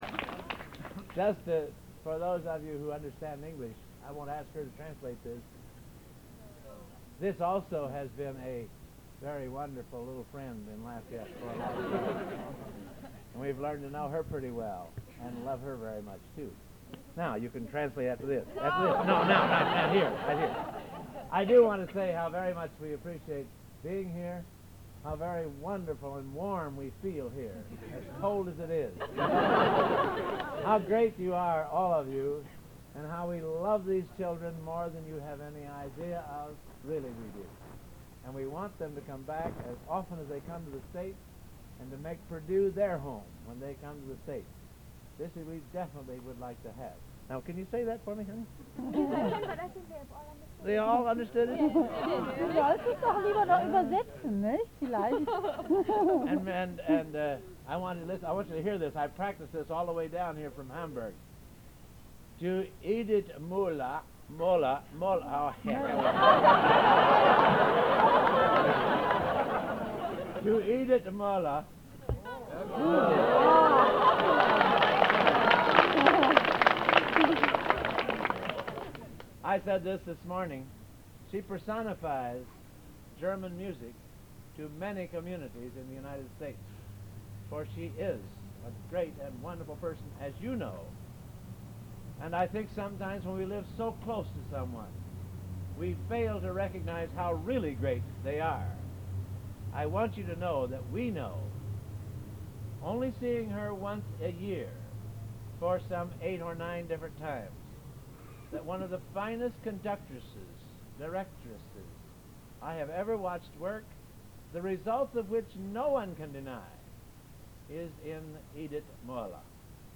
Collection: Bückeburg Garden Party
Genre: | Type: Director intros, emceeing